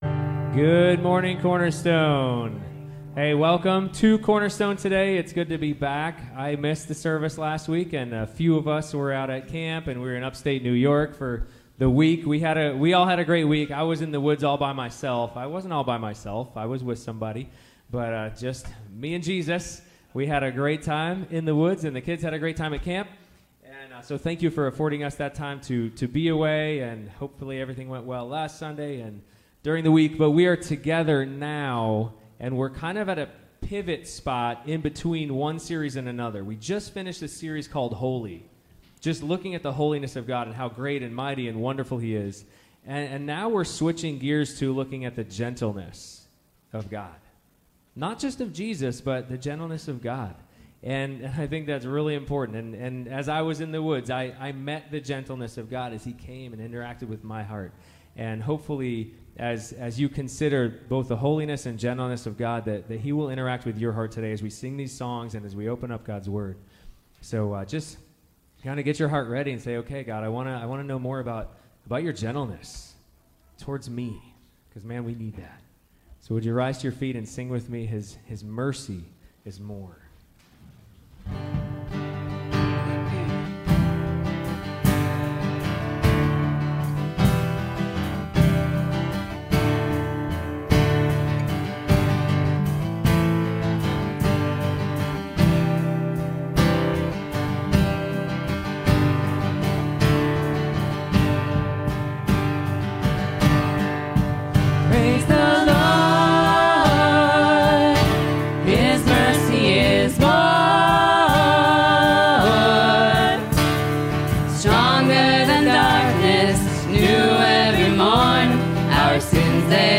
Sermon Downloads
Service Type: Sunday Morning